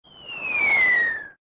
incoming_whistle.ogg